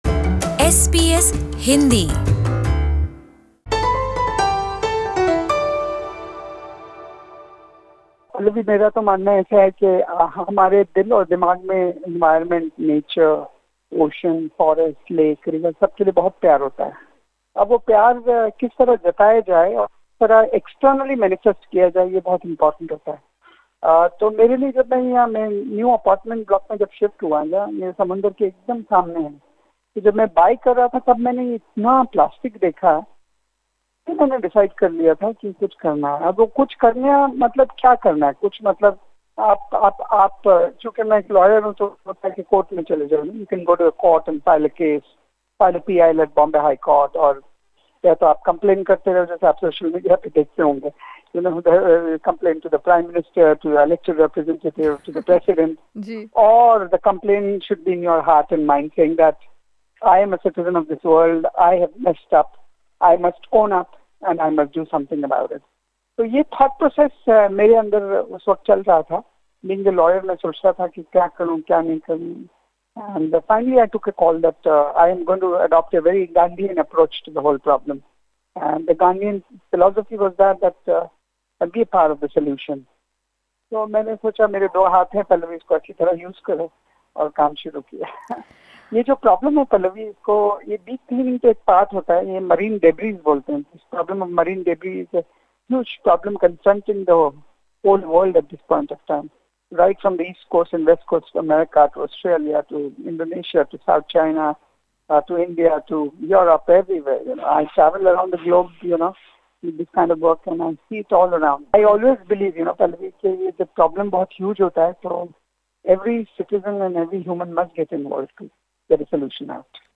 उनके इस प्रयास के चलते इस साल, 20 वर्षों से भी अधिक समय बाद, वर्सोवा बीच पर ओलिव रिडली कछुओं के बच्चे दिखाई दिए. प्रस्तुत है अफ़रोज़ शाह के साथ ये बहुत ही ख़ास मुलाक़ात